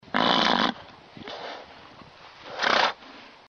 Звук фырканья величественного осла